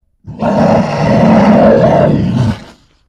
growling bear - Eğitim Materyalleri - Slaytyerim Slaytlar
growling-bear